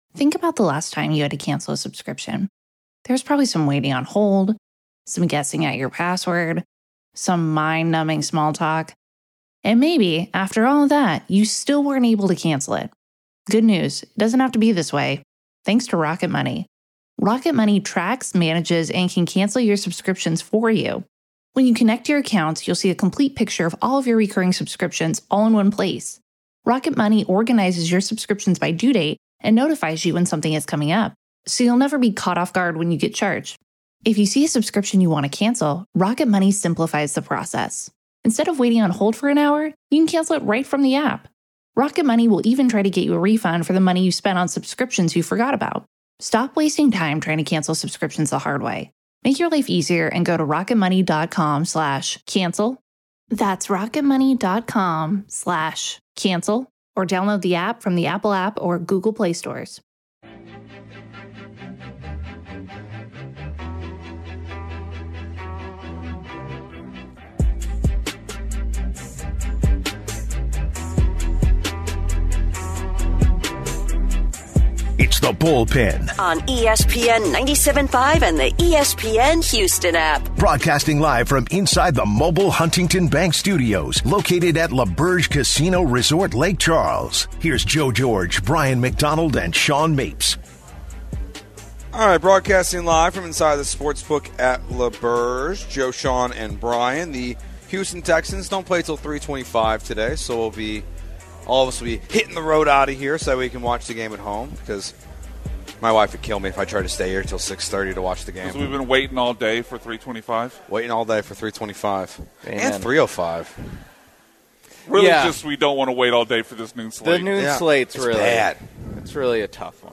12/21/25 Hour 2 (Live from L'auberge in Lake Charles) - Texans vs. Raiders Preview & DFS Picks & Mt. Rushmore +1!